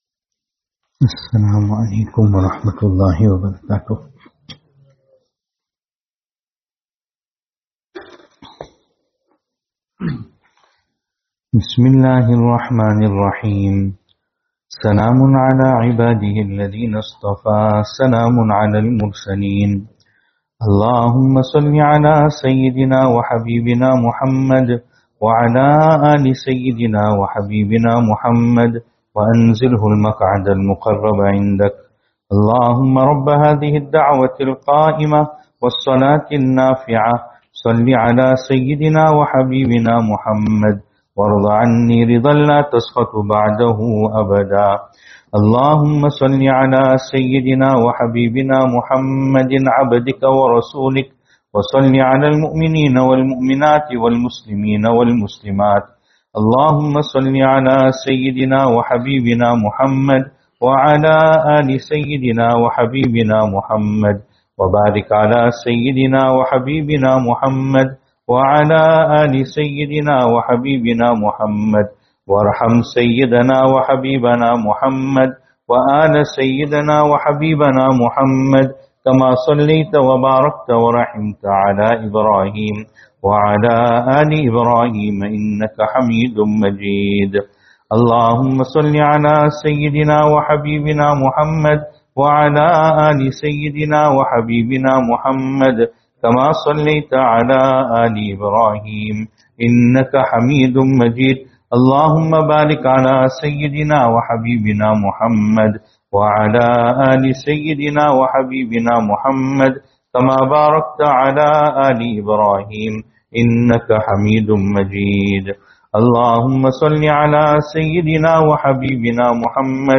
Venue: Albert Falls , Madressa Isha'atul Haq Series: Ramadaan 1447/2026 Service Type: Ramadaan 40 Durood Munajaat Advices Dua « After Taraweeh Advices – Night 23.